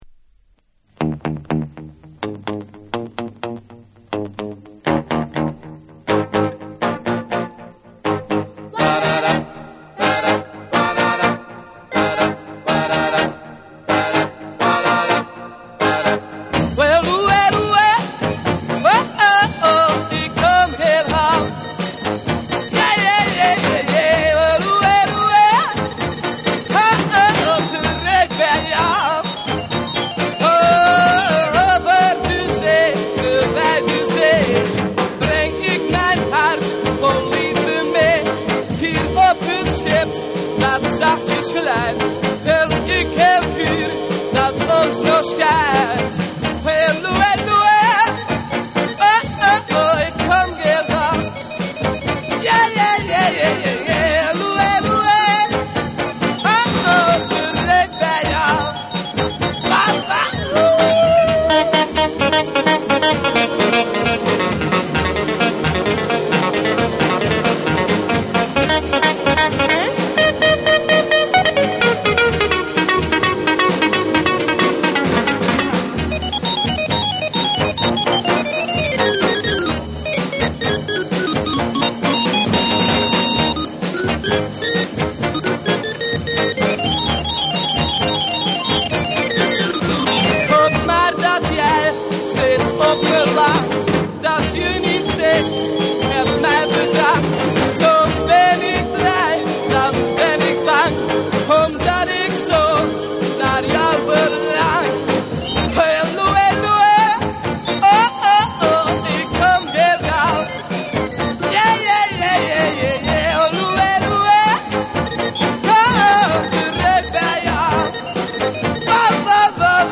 WORLD / NEW WAVE & ROCK